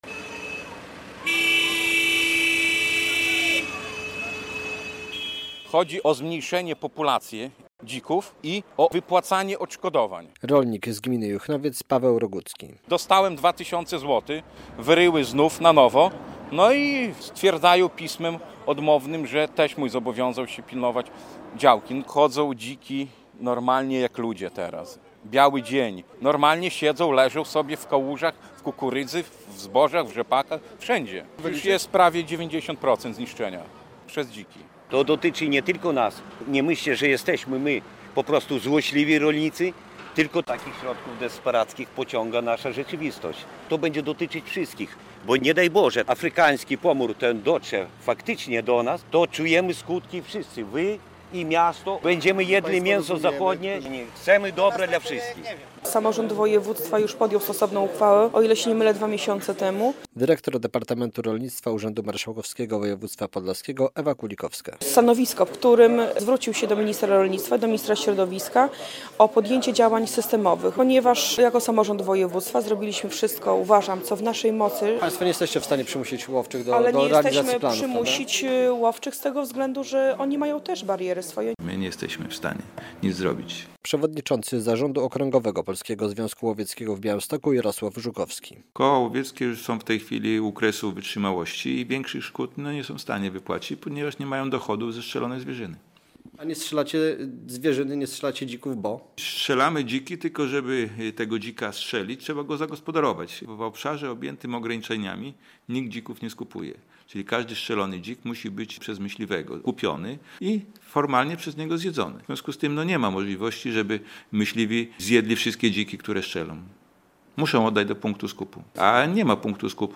Protest podlaskich rolników na DK 19 - relacja